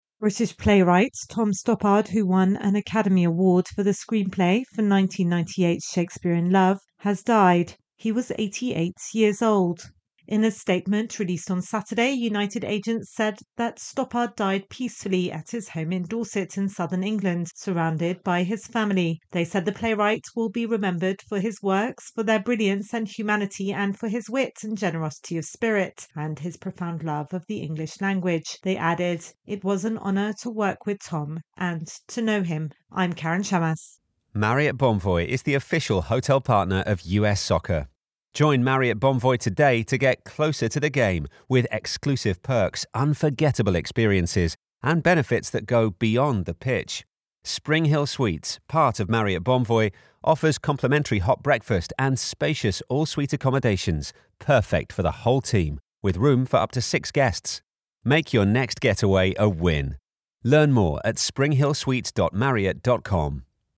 reports on the death of renowned playwright Tom Stoppard.